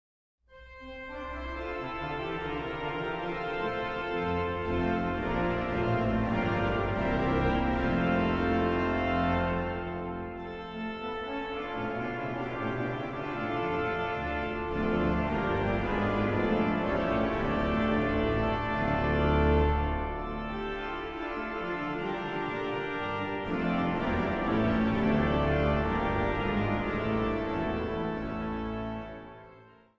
orgel.
Zang | Mannenkoor